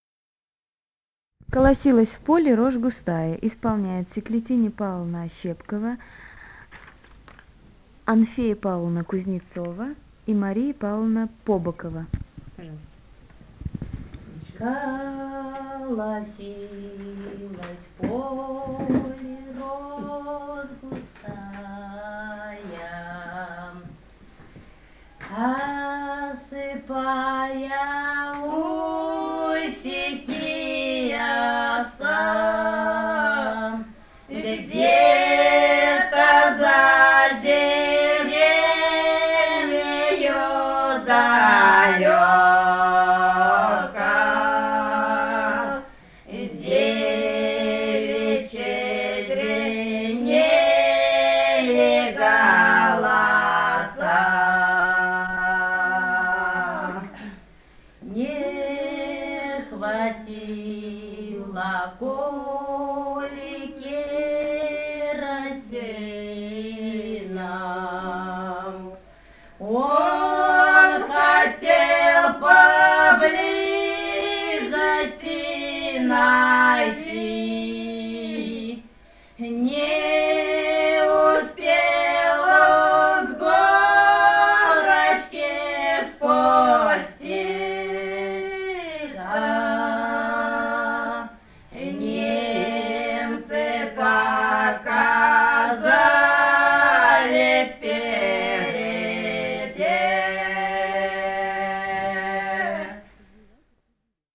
полевые материалы
Бурятия, с. Петропавловка Джидинского района, 1966 г. И0903-10